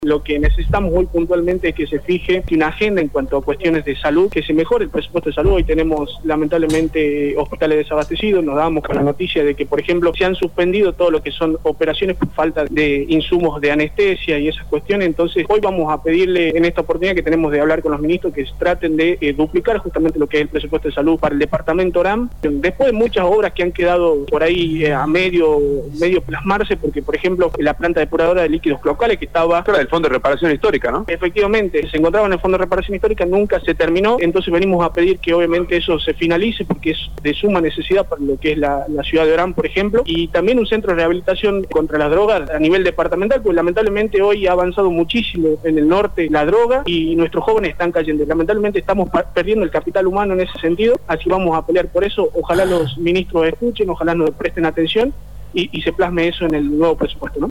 El diputado por el Departamento de Oran Iván Mizzau, comentó sobre el pedido hará en la legislatura en relación al presupuesto 2018 y obras inconclusas del Fondo de Reparación Histórica.